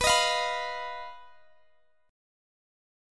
Listen to BMb5 strummed